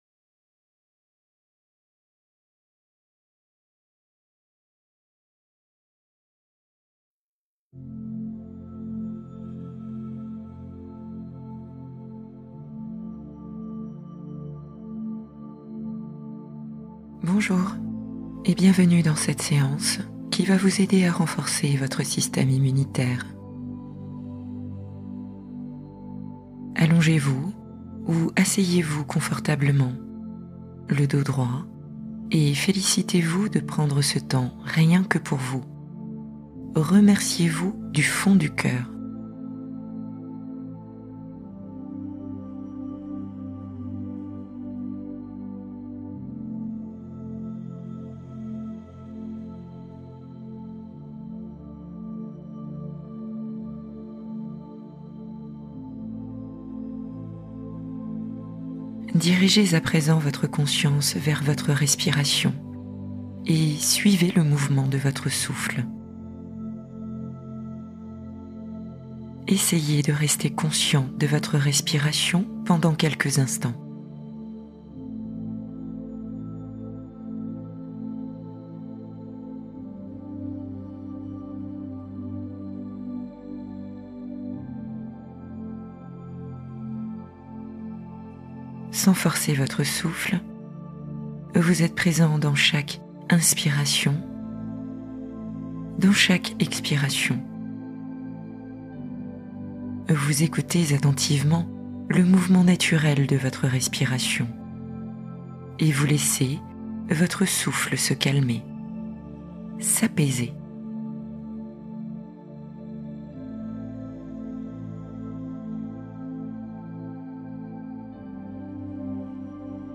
Immunité renforcée : purification guidée pour revitaliser le corps